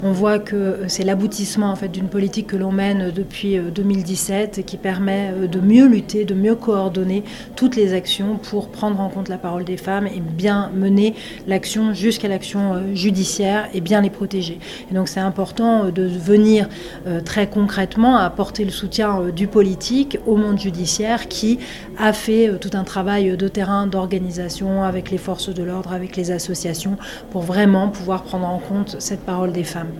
Yaël Braun-Pivet, Présidente de l'Assemblée Nationale